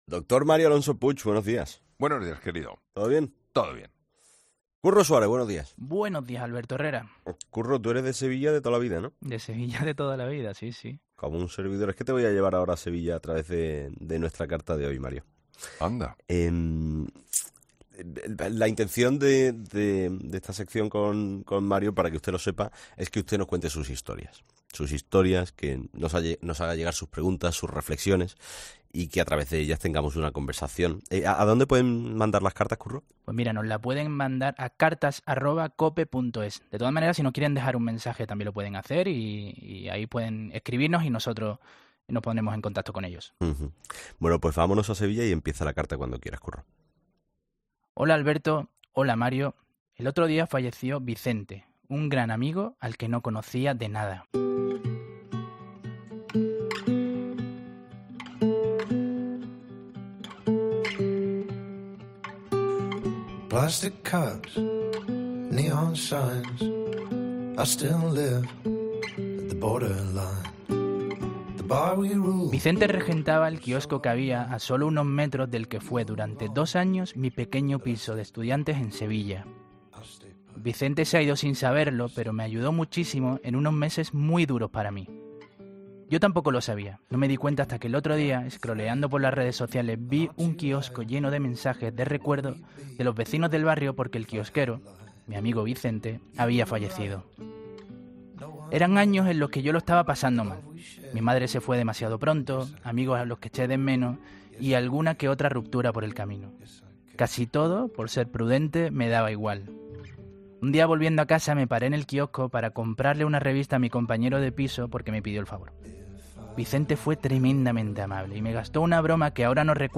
A través de una carta leída en el espacio 'Reflexionando con Mario Alonso Puig', un joven ha rendido homenaje